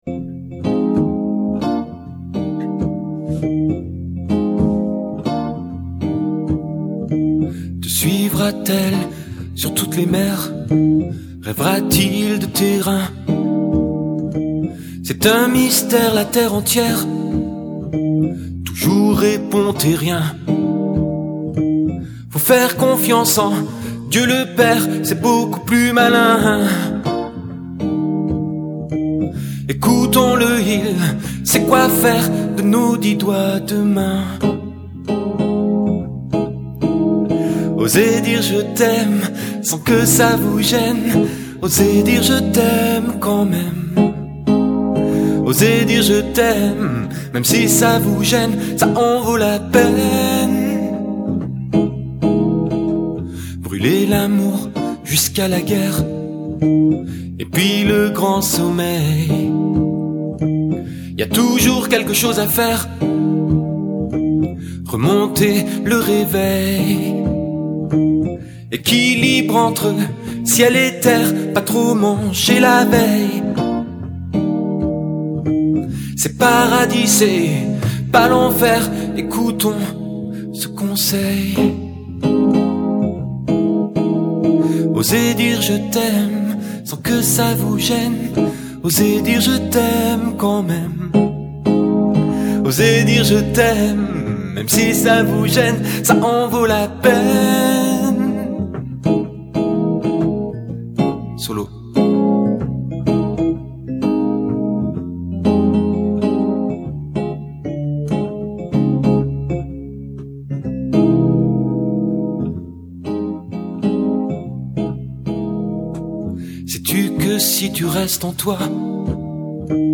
Une idée cadeau pour les musiciens et les non musiciens amoureux de guitare rock !
Téléchargez librement les chansons d’amour écrites et interprétées par